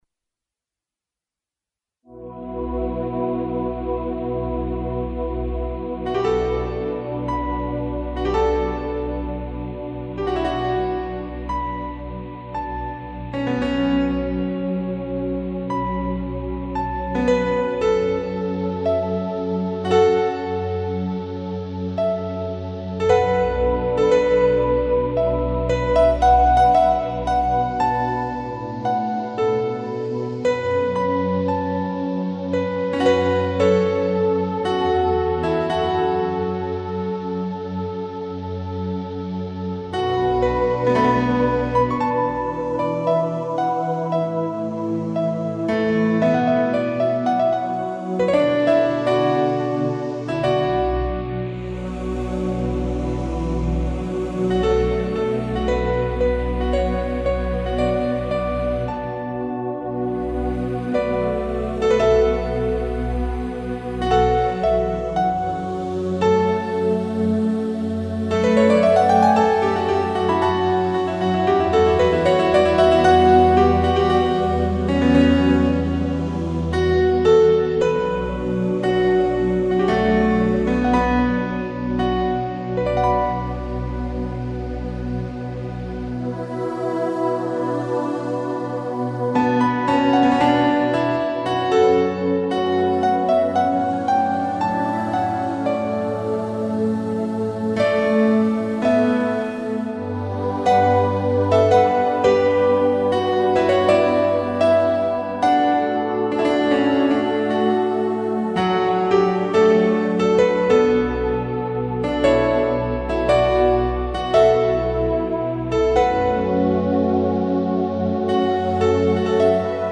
for contemplation, stress management and relaxation.
Emotionally soothing and spiritually uplifting.